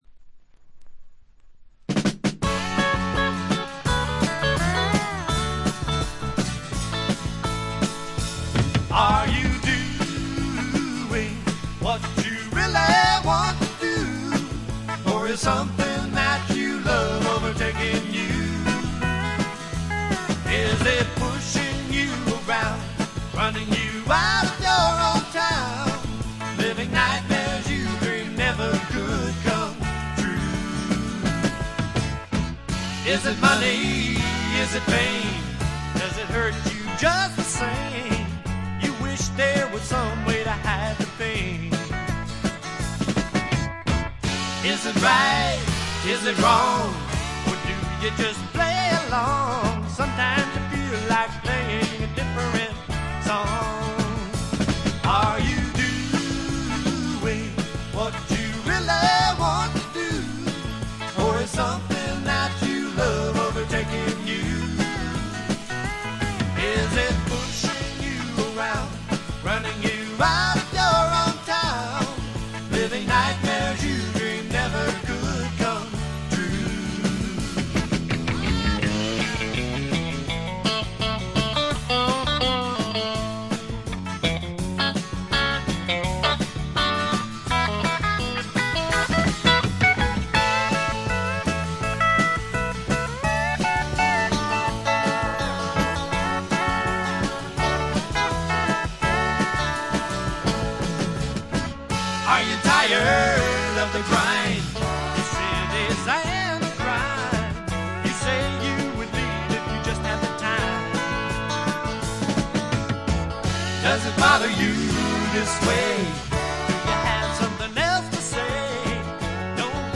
ほとんどノイズ感無し。
サンディエゴのシンガー・ソングライターによる自主制作盤。
試聴曲は現品からの取り込み音源です。